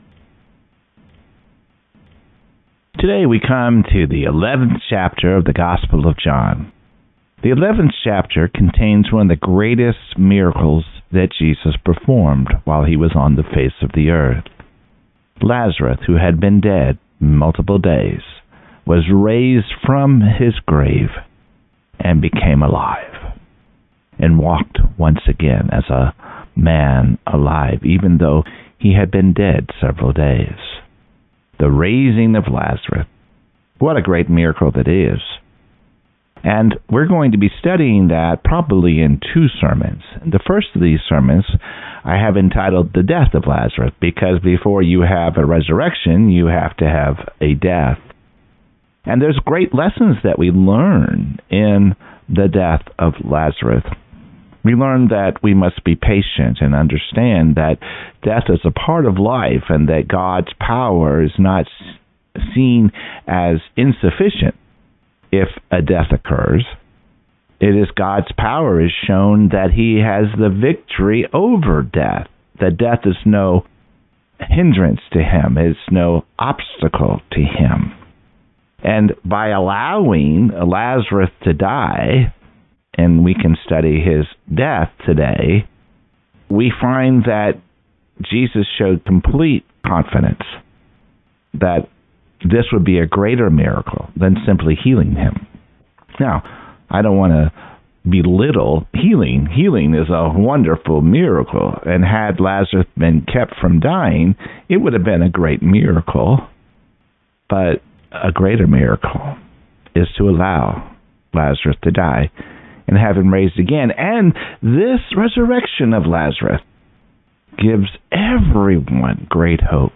John 11:1-16, Lazarus’ Death May 3 In: Sermon by Speaker